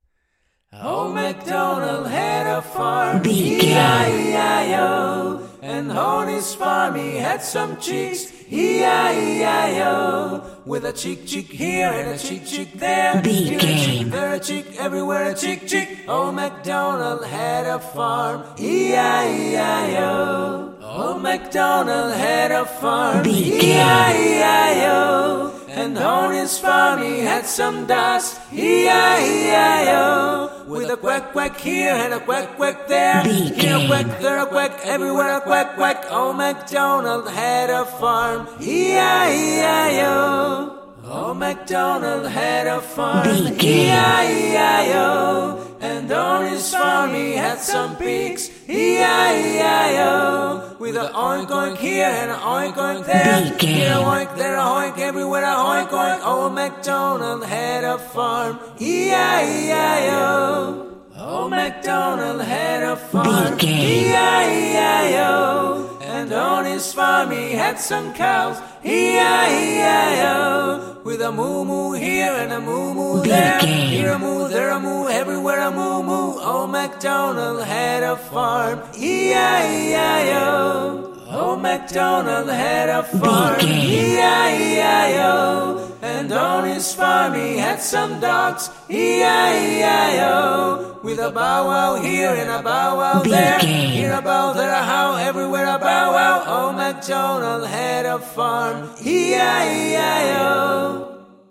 Ionian/Major
G♭
nursery rhymes
fun
childlike
cute
happy